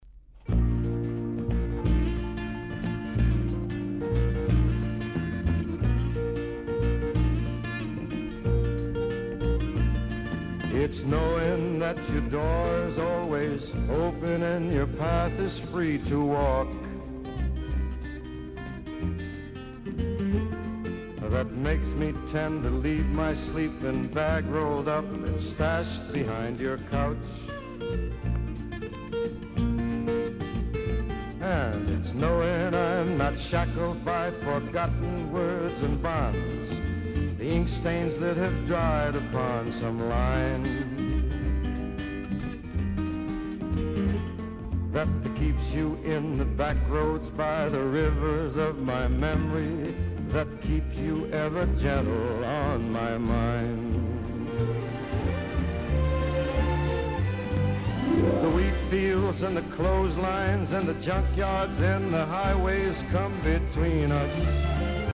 アメリカを代表する男性シンガー。
VG++〜VG+ 少々軽いパチノイズの箇所あり。クリアな音です。